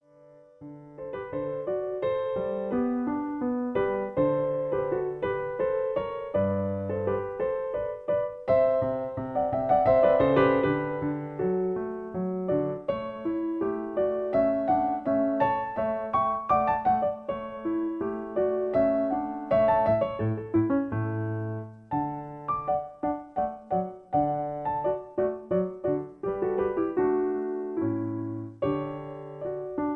Original key. Faster tempo. Piano Accompaniment